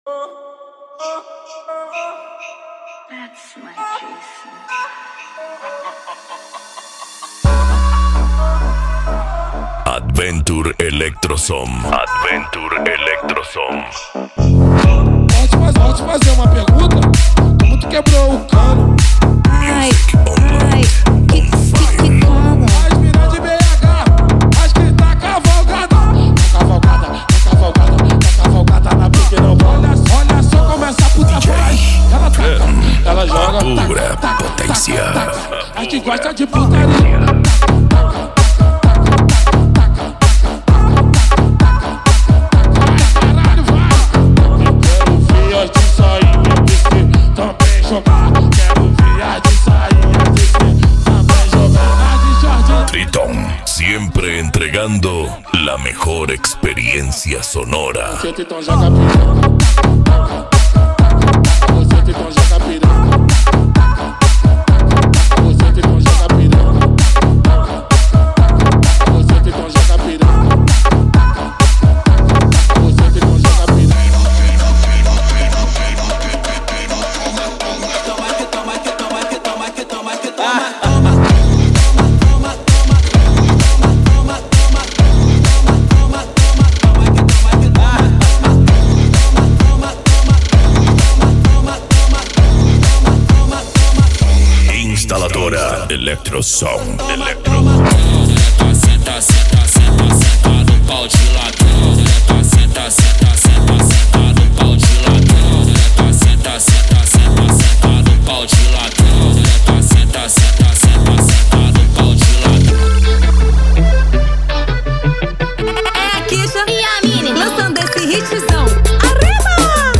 Eletronica
Psy Trance
Remix